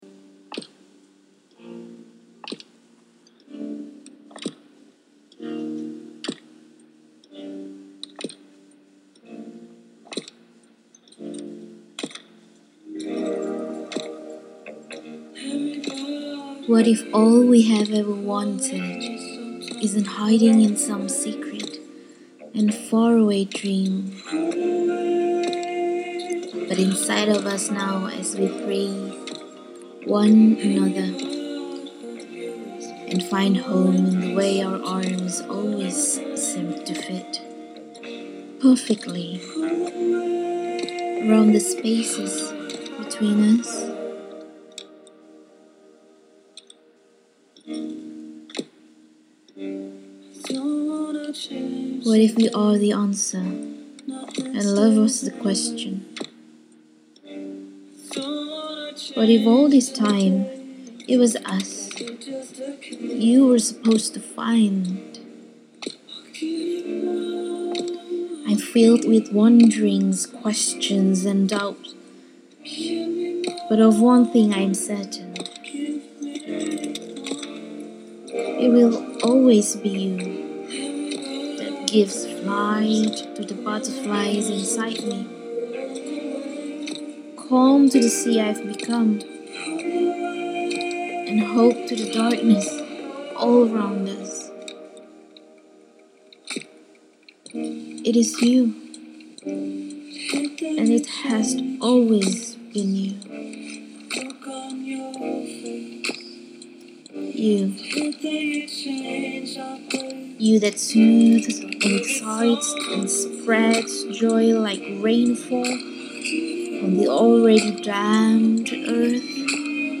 Sounding the poem, dancing with melody
• Music: J.Views – Don’t Pull Away (ft. Milosh)